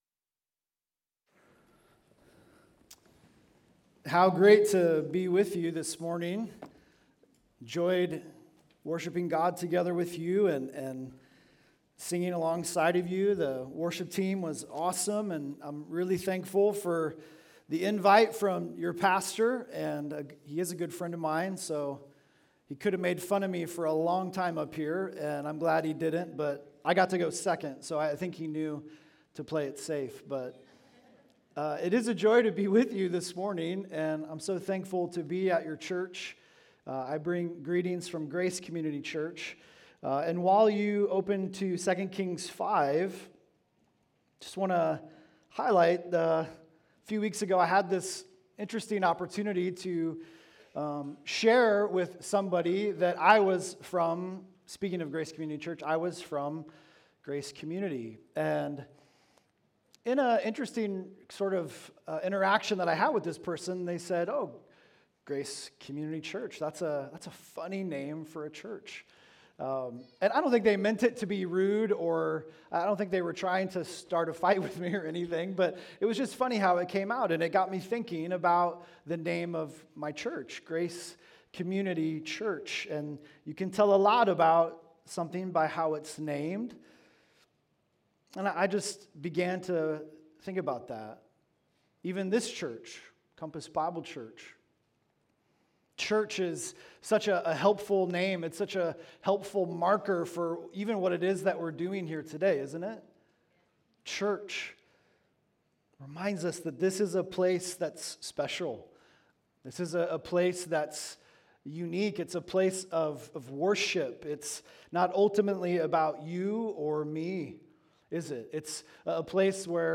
Wash and Be Clean (Sermon) - Compass Bible Church Long Beach